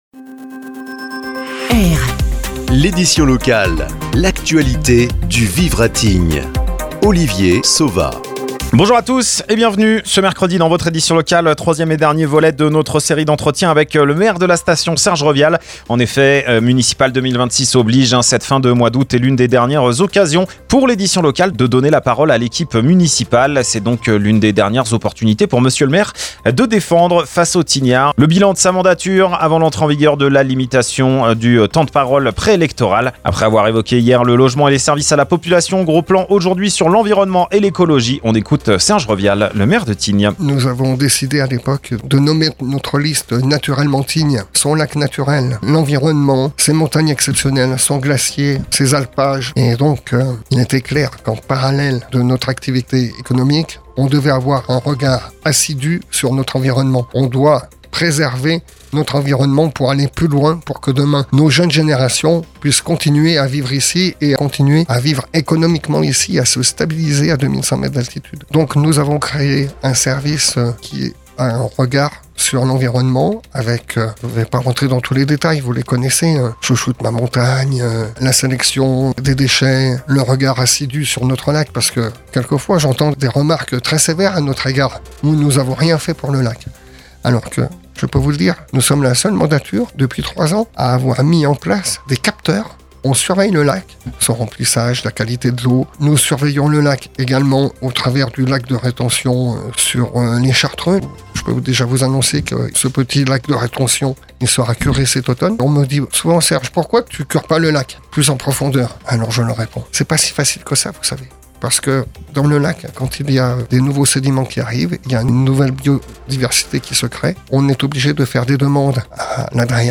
– Ce mercredi dans votre édition locale troisième et dernier volet de notre série d’entretiens avec le maire de la station Serge Revial.